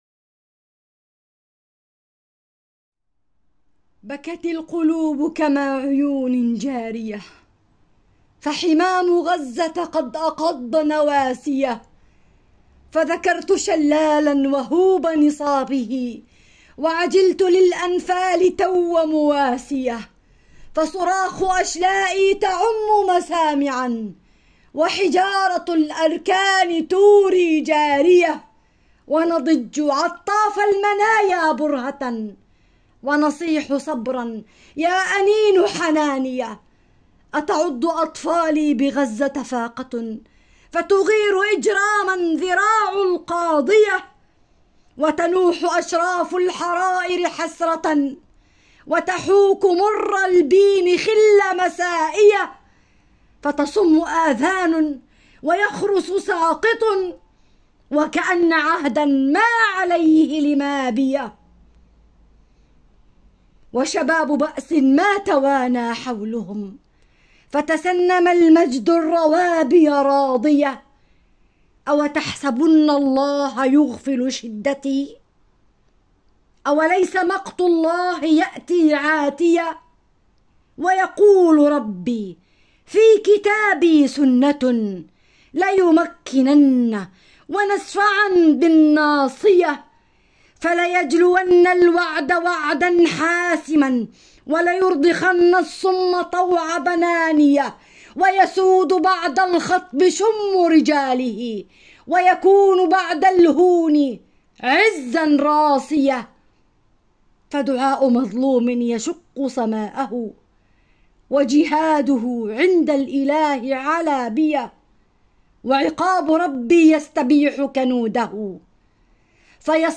أبيات شعرية من أجل غزة القسم النسائي - الأرض المباركة